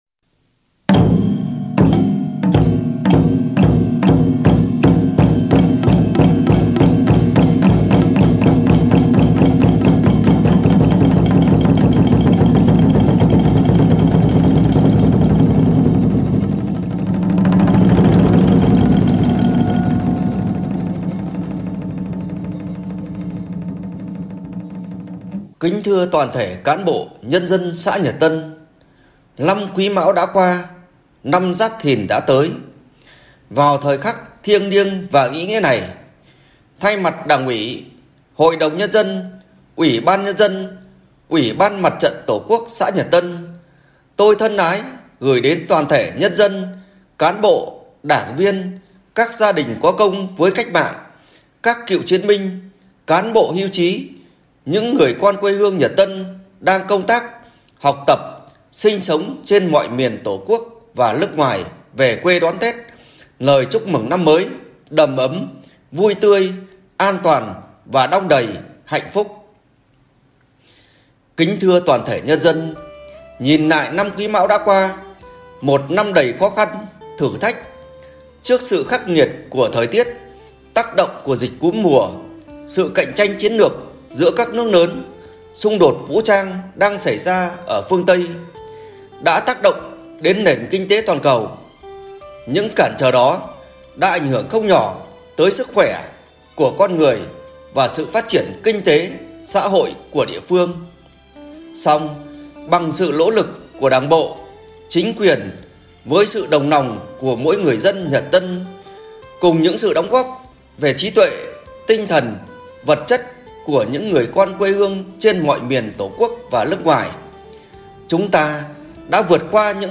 ĐỒNG CHÍ PHẠM QUANG TẦN, CHỦ TỊCH UBND XÃ CHÚC TẾT GIÁP THÌN 2024.
Vào thời khắc giao thừa đón mừng năm mới Giáp Thìn 2024, đồng chí Phạm Quang Tần, Phó bí thư Đảng ủy, Chủ tịch ủy ban nhân dân xã chúc tết cán bộ, Đảng viên cùng nhân dân trong xã trên Đài truyền thanh xã...